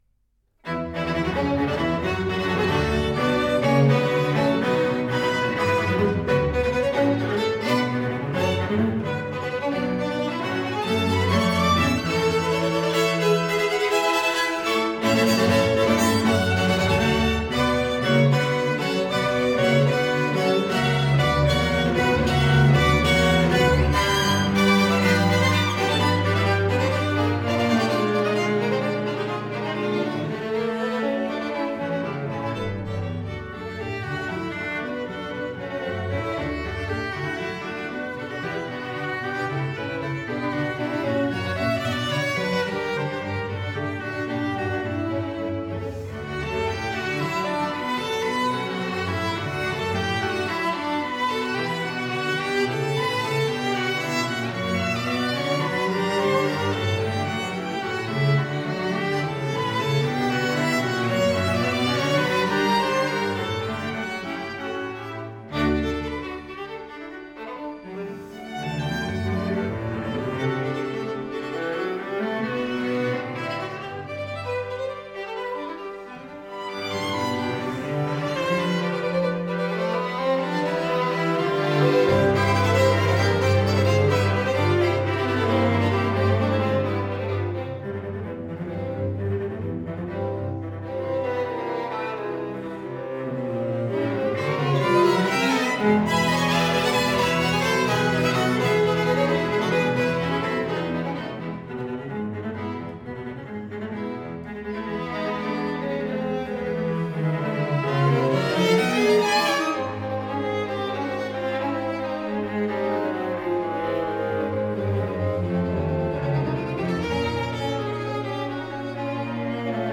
Violine
Viola
Cello